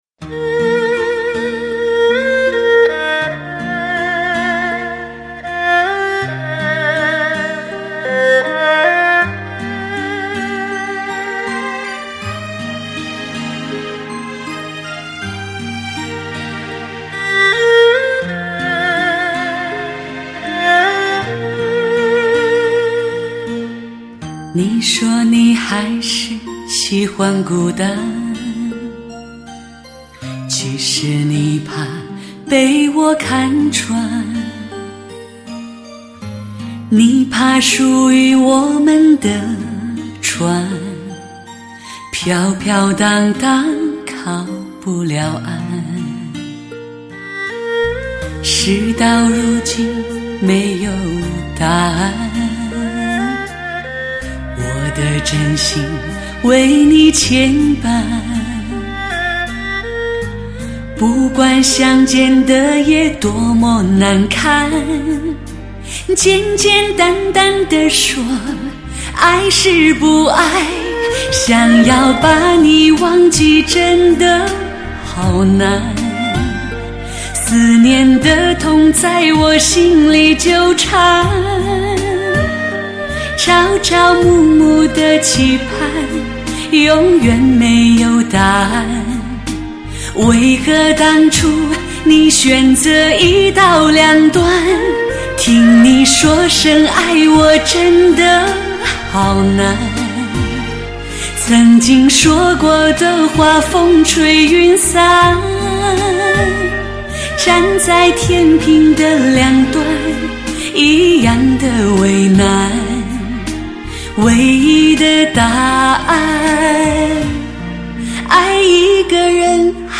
专辑格式：DTS-CD-5.1声道
音响名家挑选的“最佳音效示范唱片”
全面回复黑胶唱片密度感和耐听感